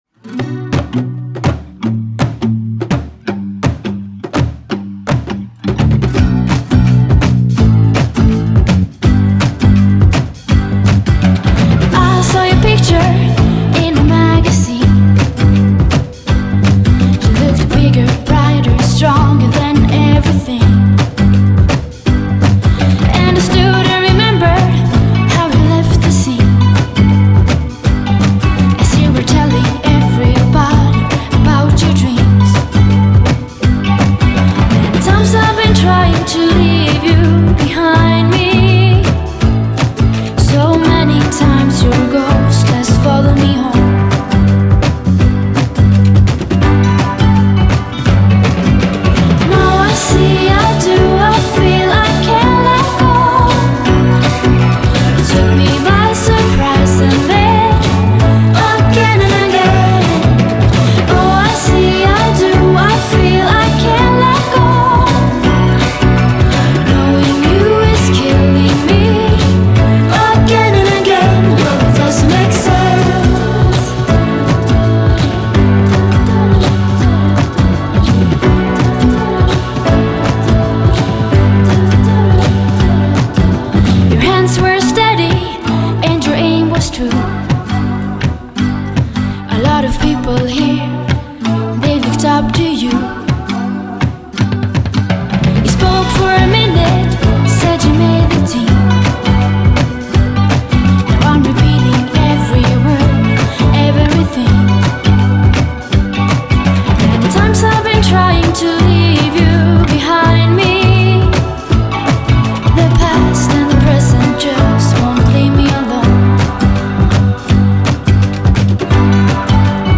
Swedish singer and musician.
Genre: Indie, Pop